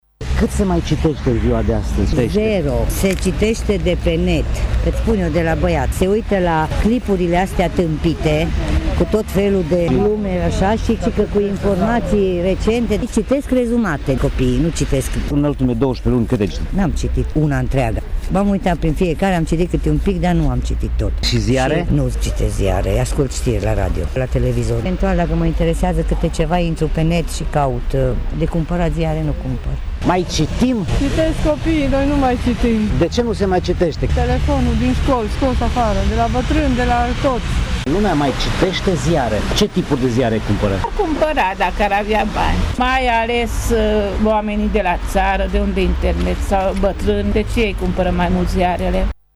Oamenii recunosc că nu prea mai citesc cărți, ci eventual culeg informație de pe internet: